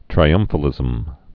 (trī-ŭmfə-lĭzəm)